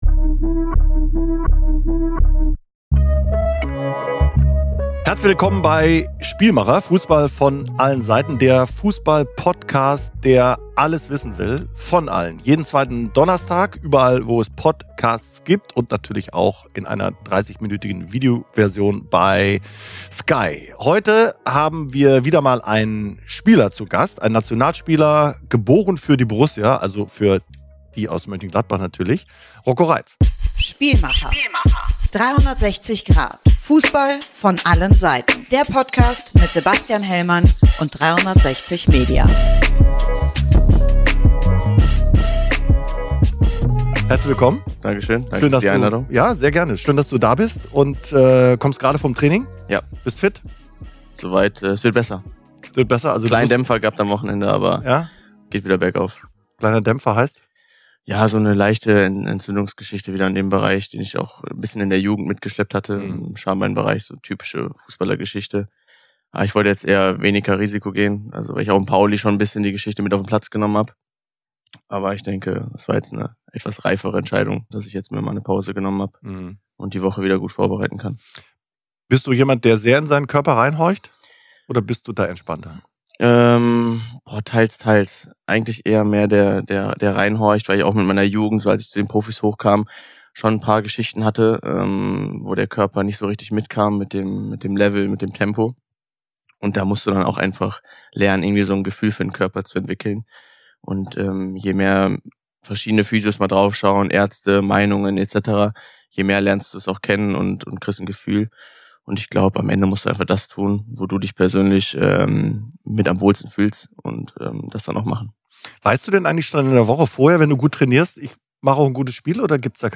Rocco Reitz spricht in dieser Folge „Spielmacher“ mit Sebastian Hellmann über seinen ganz speziellen Weg in den Profifußball – vom ersten Fußball-Camp an Ostern bis zum Gänsehaut-Moment bei der Nationalmannschaft. Er redet über seinen hitzigen Sitznachbarn Tim Kleindienst und seinen Job als Kassenwart bei Borussia Mönchengladbach. Und auch einige seiner prägendsten Weggefährten sind in dieser Folge dabei: Lars Stindel, Marco Rose und Bernd Hollerbach mit Anekdoten und Gedanken zum kleinen und großen Rocco Reitz.